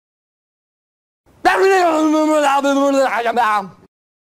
Glb Glb Glb Incomprehensible Sentence Sound
meme